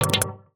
UIClick_Menu Reject Single 03.wav